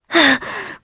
Priscilla-Vox_Damage_jp_01.wav